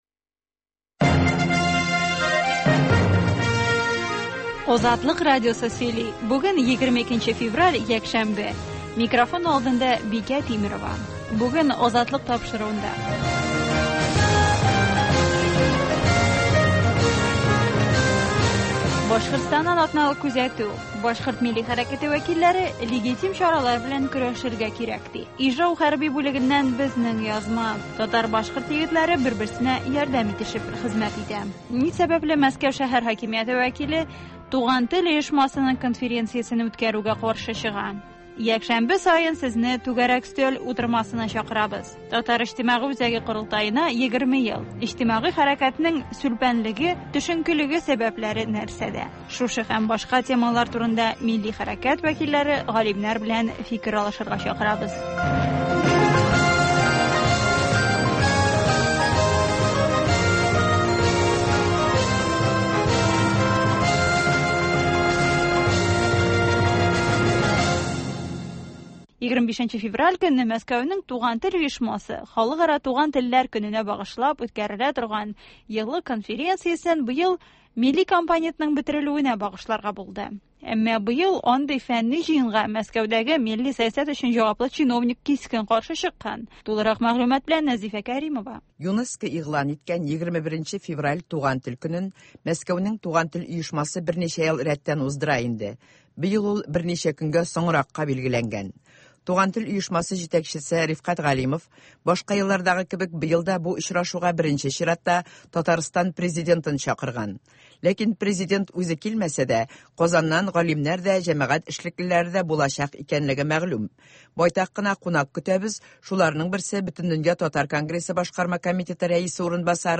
Азатлык узган атнага күз сала - башкортстаннан атналык күзәтү - татар дөньясы - түгәрәк өстәл артында сөйләшү